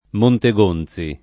monteg1nZi] top. (Tosc.) — con -z- sorda nell’uso loc., dovuta (come nell’altro top. tosc. Collegonzi) all’origine da un pers. m. germanico Gonzo — forte però nella stessa Tosc. la tendenza a lèggere con -z- sonora per attraz. del s. m. gonzo, che non c’entra